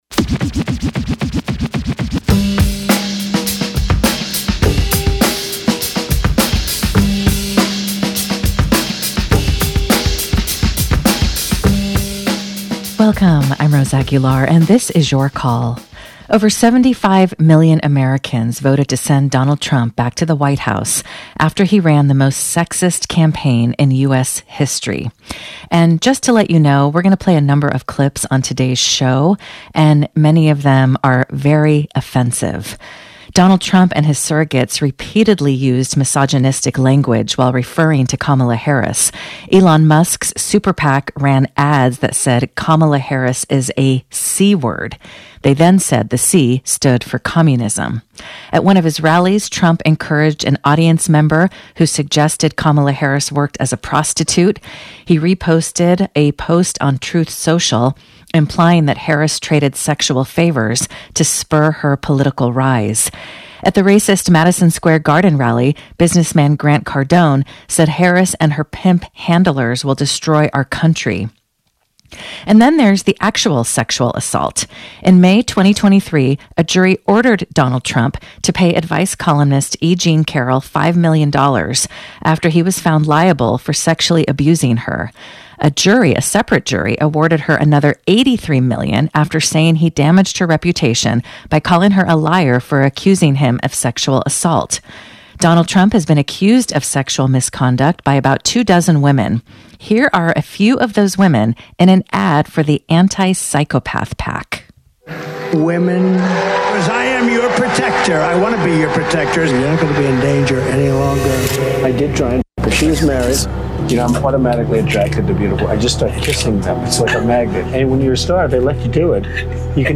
KALW's call-in show: Politics and culture, dialogue and debate.